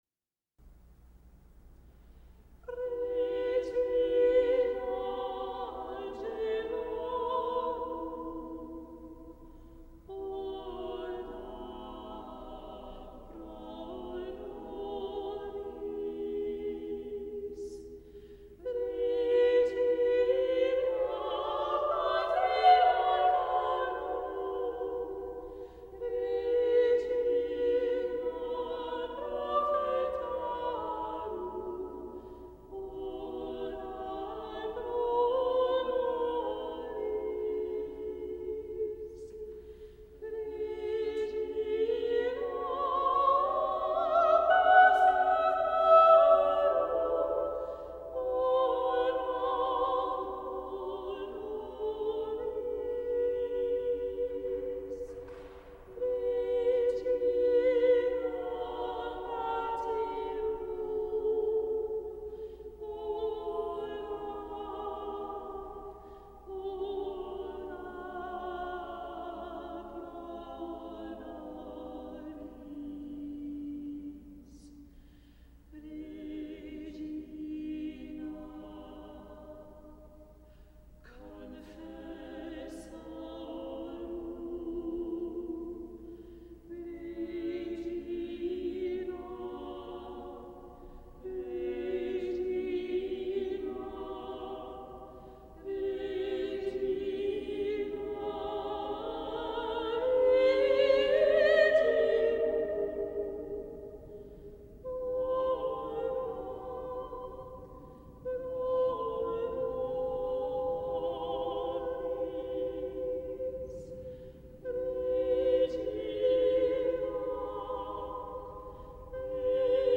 Regina Angelorum Read through - 8 voices